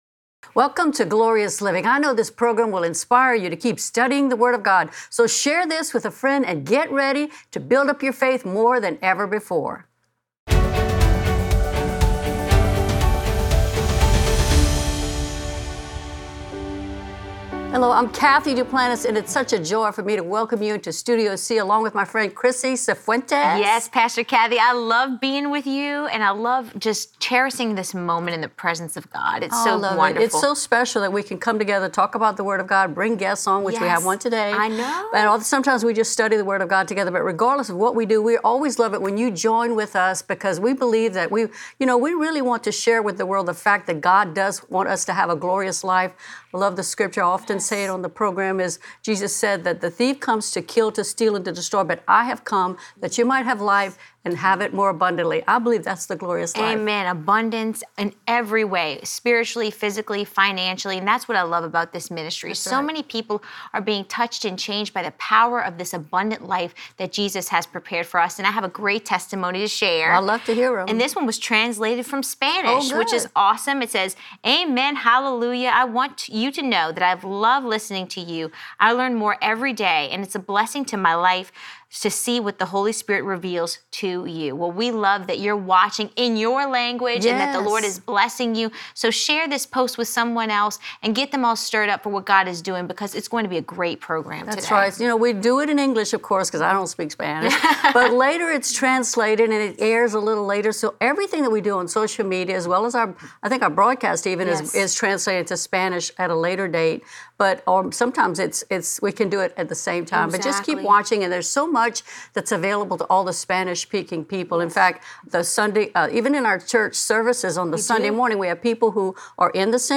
in Studio C as they discuss how the JDM Spanish Outreach is expanding more than ever before!